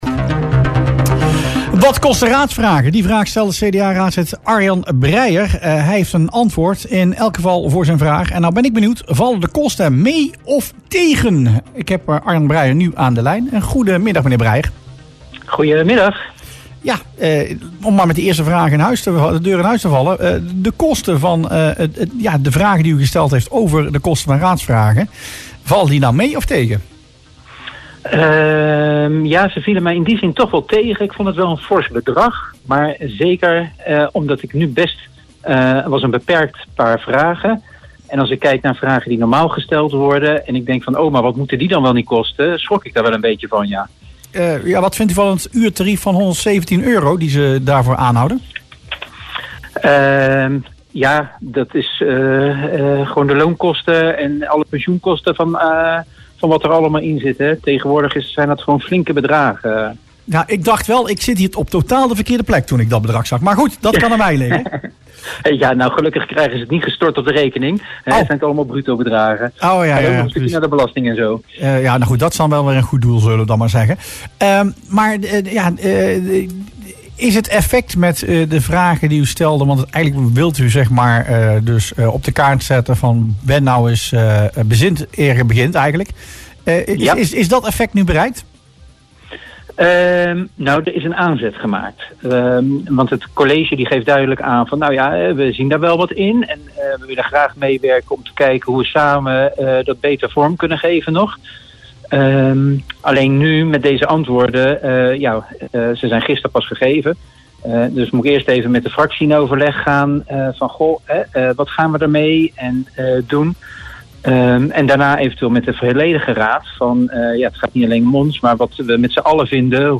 LAND VAN CUIJK – De kosten voor het beantwoorden van raadsvragen vallen hoger uit dan verwacht, stelt CDA-raadslid Arjan Breijer in het radioprogramma Rustplaats Lokkant op Omroep Land van Cuijk. Hij pleit daarom voor scherpere formuleringen, zodat het werk van de gemeenteraad efficiënter en goedkoper kan worden uitgevoerd.
CDA-raadslid Arjan Breijer in Rustplaats Lokkant